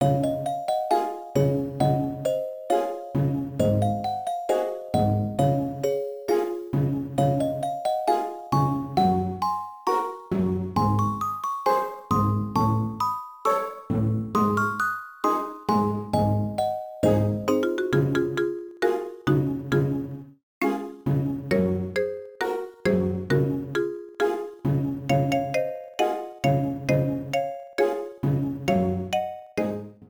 Ripped from game data, then trimmed in Audacity